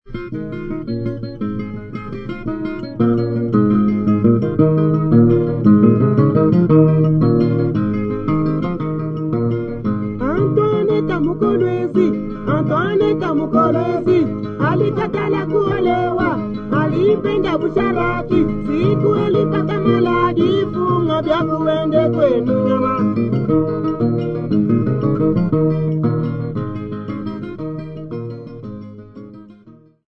Folk music--Africa
Field recordings
sound recording-musical
Indigenous topical folk song with singing accompanied by 2 guitars and the sound of a struck bottle.